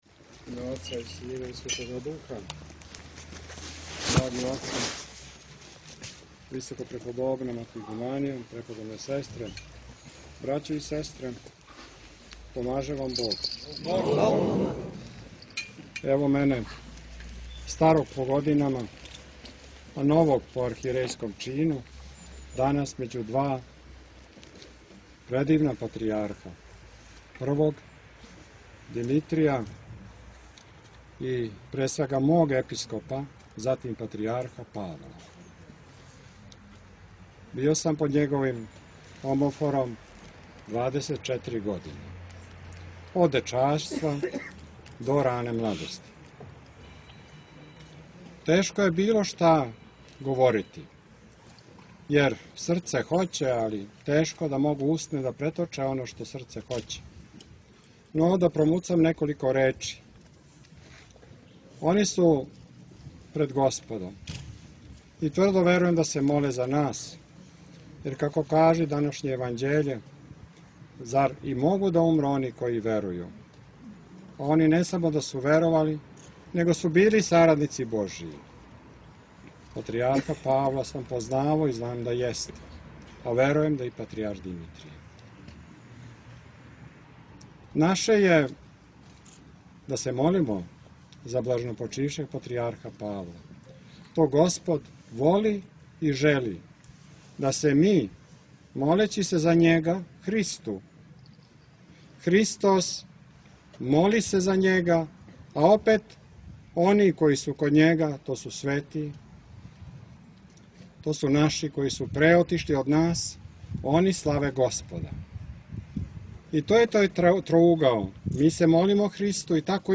Архијерејска Литургија и помен Патријарху Павлу у манастиру Раковица